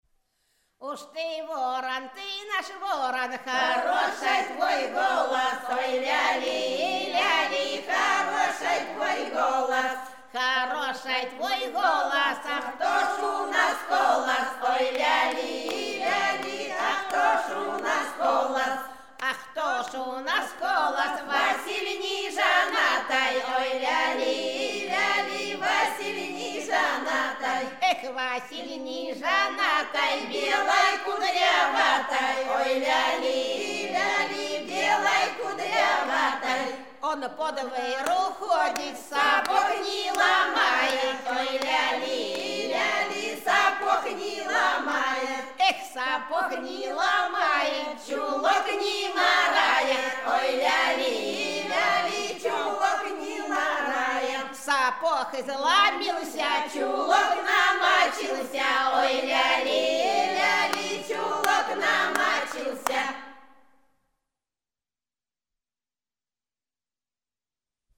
Рязань Секирино «Уж ты ворон», свадебная, обыгрывание дружка.